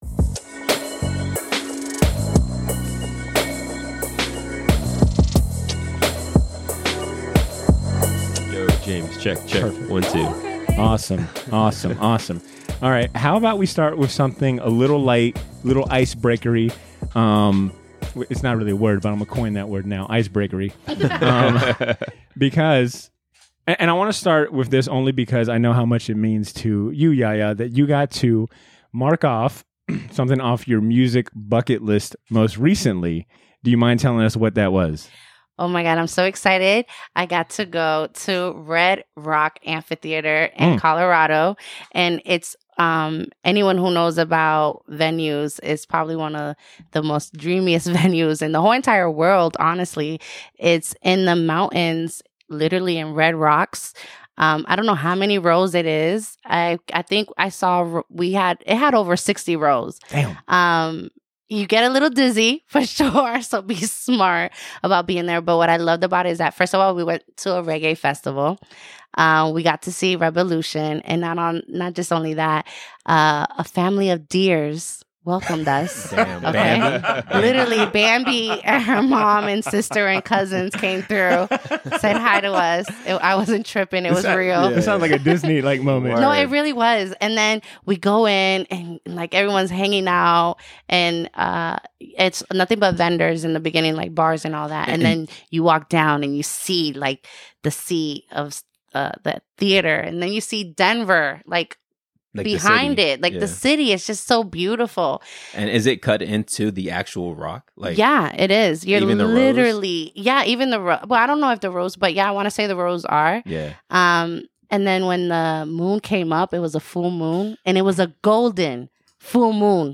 joins the crew for a conversation centered around their personal favorite (and most impactful) movie soundtracks and film scores, and the show's first giveaway is announced!